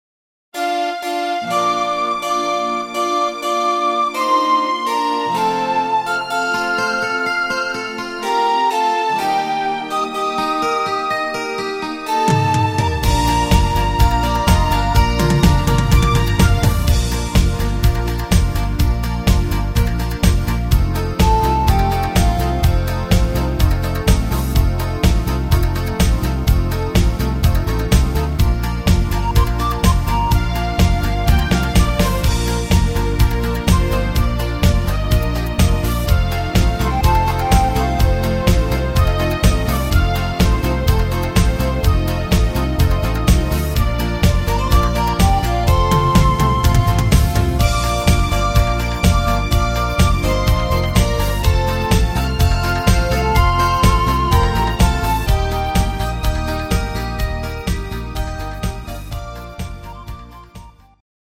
Rhythmus  Disco Marsch
Art  Schlager 90er, Deutsch